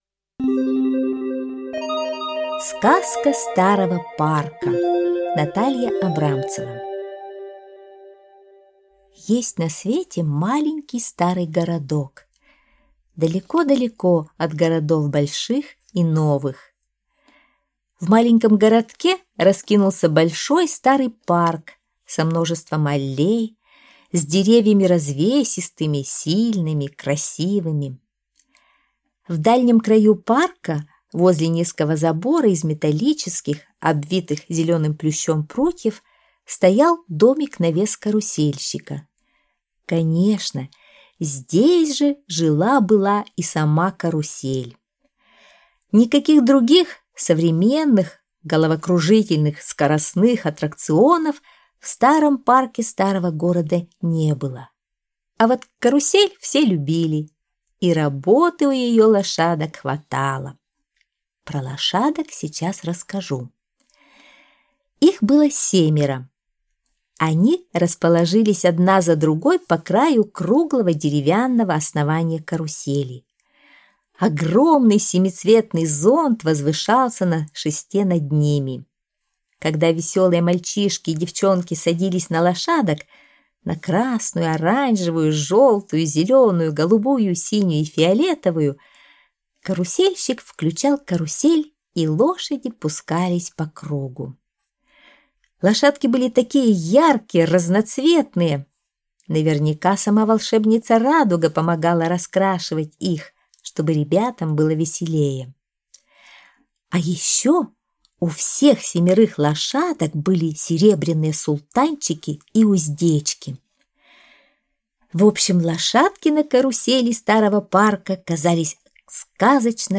Сказка старого парка (аудиоверсия)